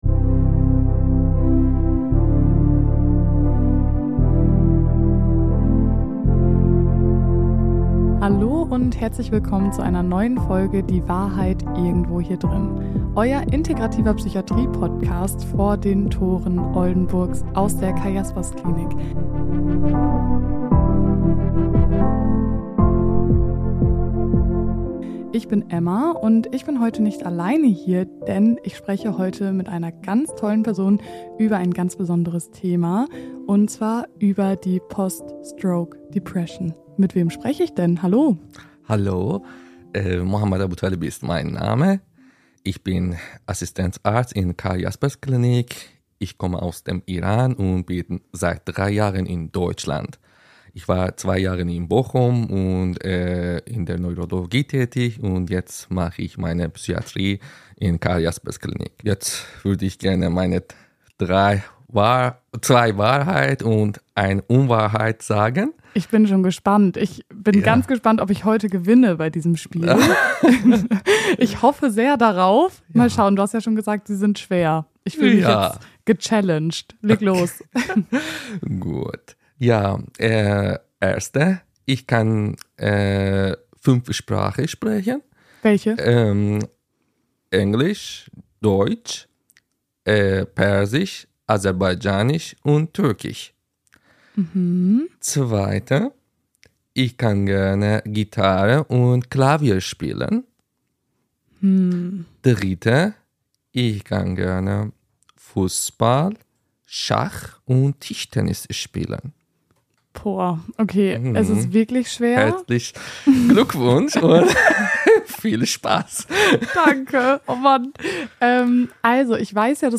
Experten-Talk